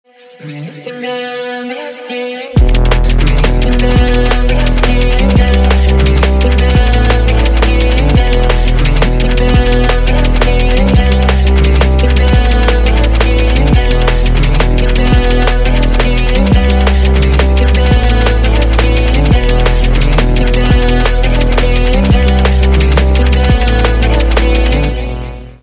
электроника
грустные
битовые , басы